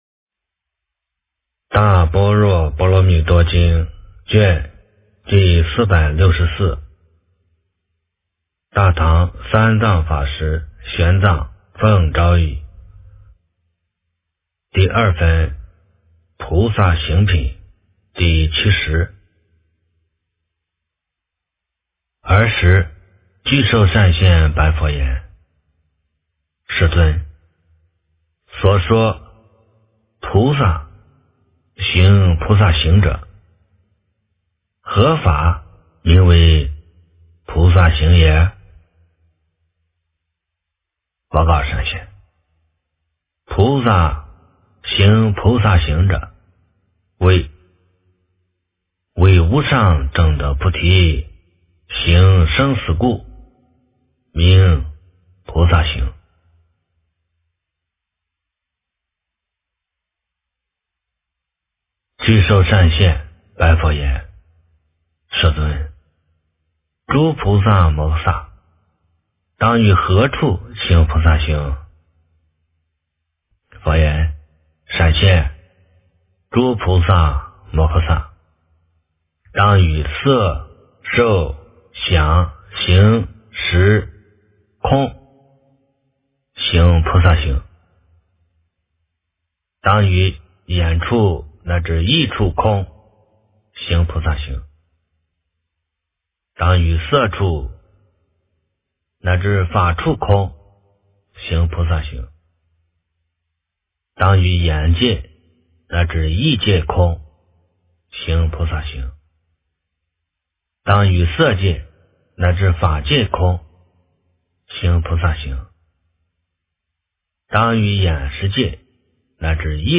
大般若波罗蜜多经第464卷 - 诵经 - 云佛论坛